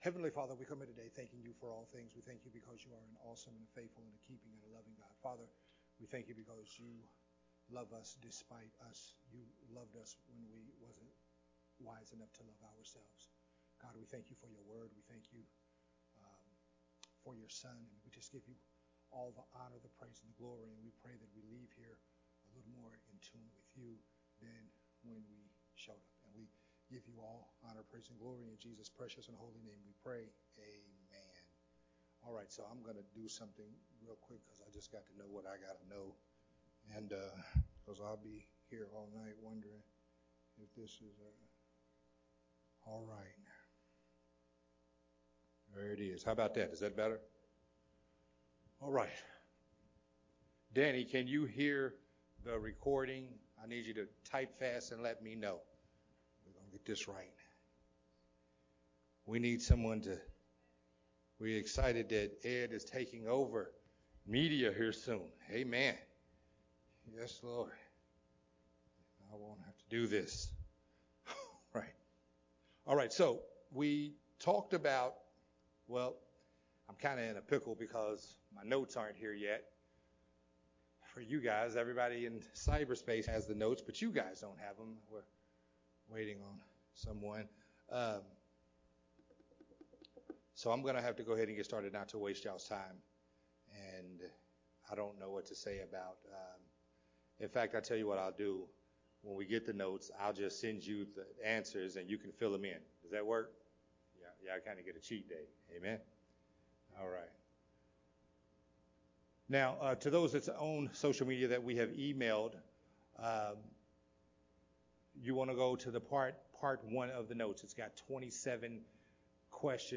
Recorded at Unity Worship Center on October 20th, 2021.